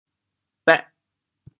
潮州 bêh8 ci3 hê5 潮阳 bêh8 ci3 hê5 潮州 0 1 2 潮阳 0 1 2
bêh8.mp3